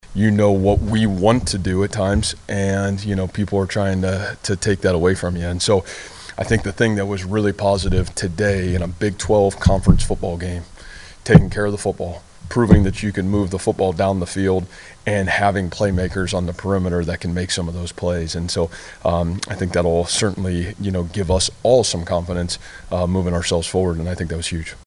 ISU coach Matt Campbell.